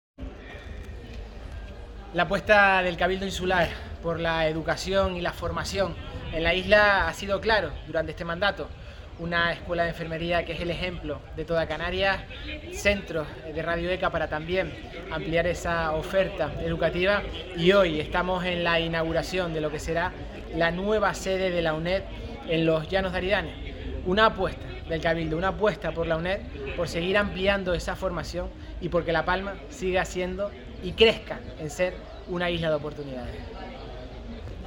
Por su parte, el presidente insular, Mariano Zapata, defendió que el Cabildo mantiene su compromiso con las personas de la Isla, especialmente con el alumnado que desarrolla en las instalaciones de la UNED su actividad lectiva.
Declaraciones  audio Mariano Zapata.mp3